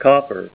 Help on Name Pronunciation: Name Pronunciation: Copper